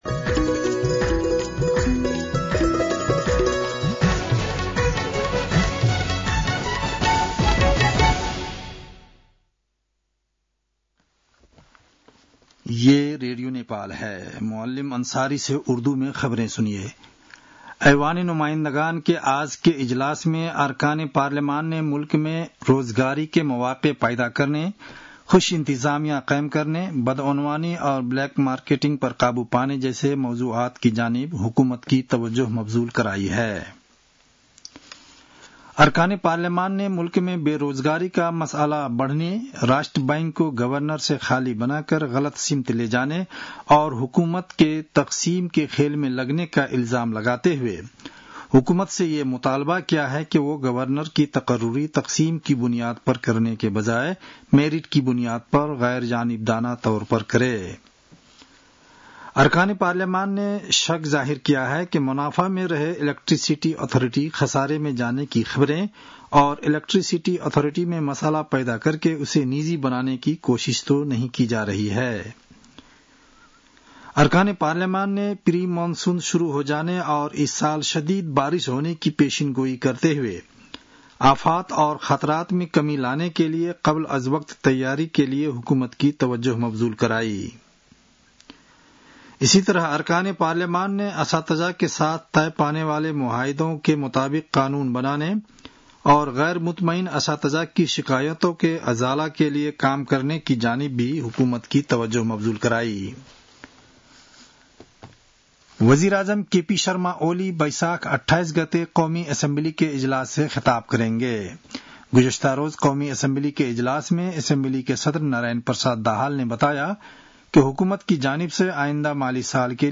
उर्दु भाषामा समाचार : २४ वैशाख , २०८२
Urdu-NEWS-01-24.mp3